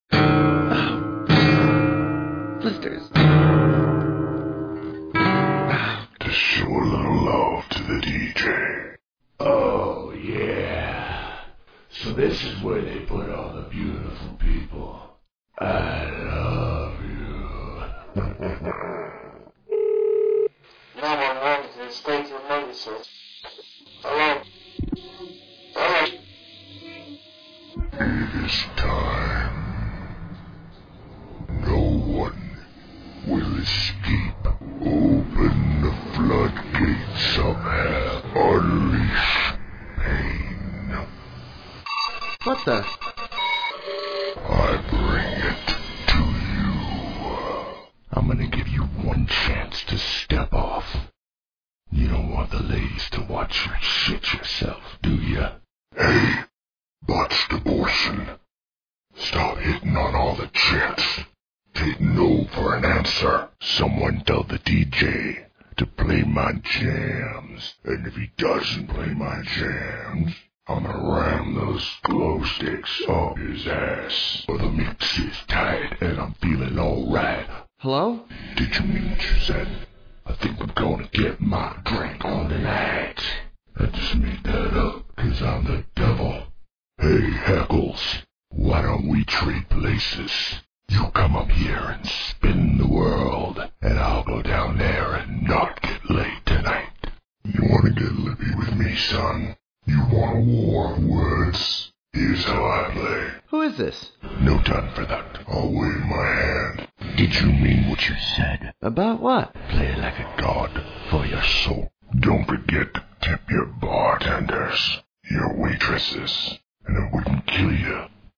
"Freaky Vocals" is a creepy and funny collection of Halloween dedicated samples, including devilish, demonic, dirty voices ready to be dropped in any kind of production.
(the prelisten files are in a lower quality than the actual packs)
flph_freakyvocals2_showcase.mp3